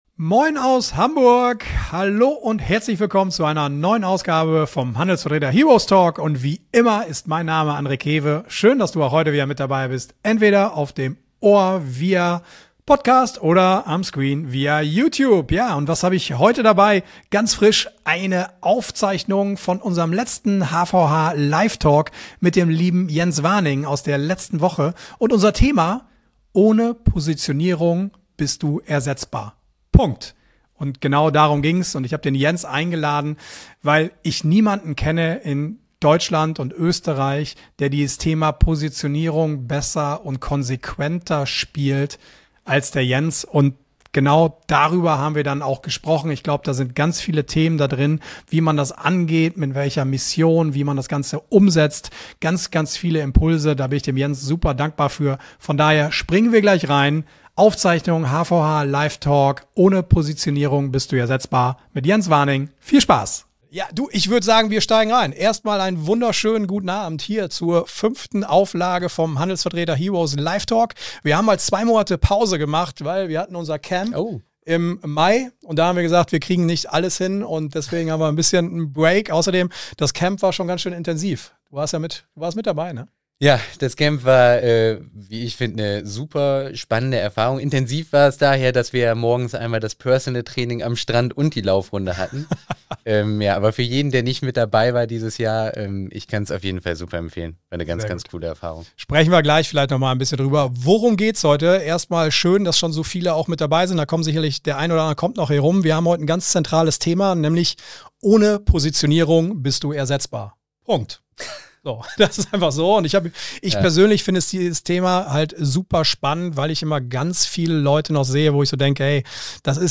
HVH Live Talk